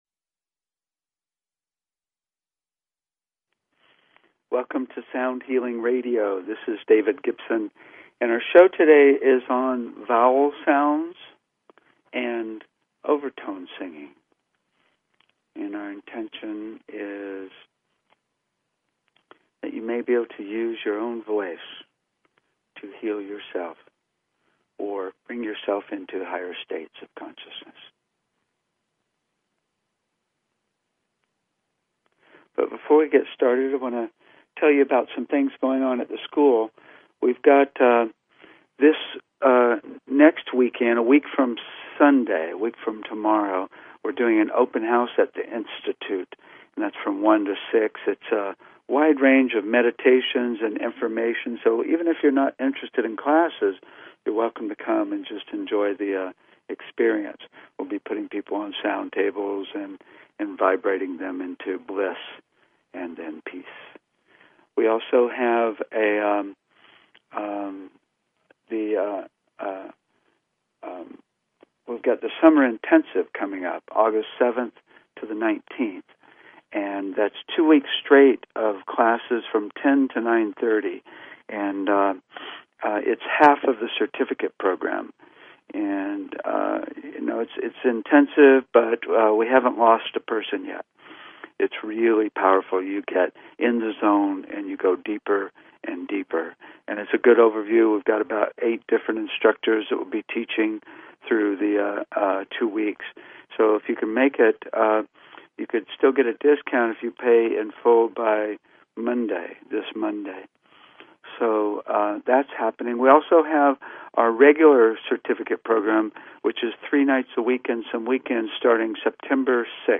Talk Show Episode, Audio Podcast, Sound_Healing and Courtesy of BBS Radio on , show guests , about , categorized as
We discuss how doing or listening to overtone singing can take you into higher states of consciousness. We explain some techniques for doing overtone singing and do a lot of overtone singing.